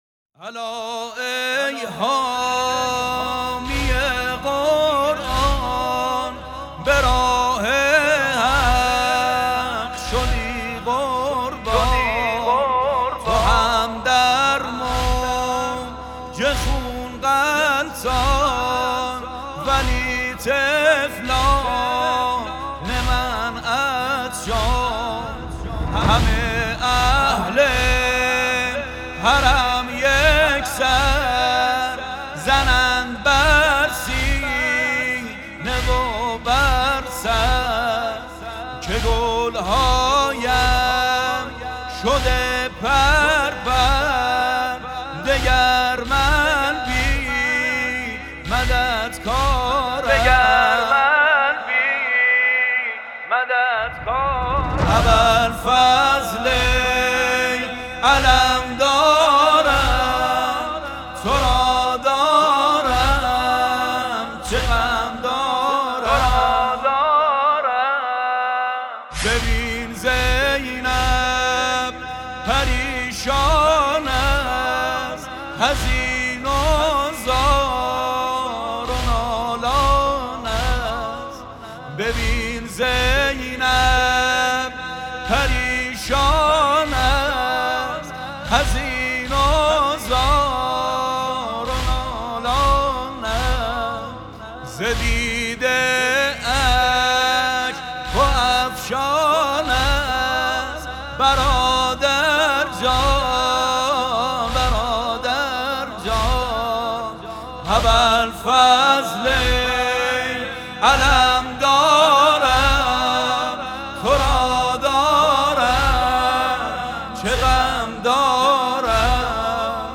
نوحه شیرازی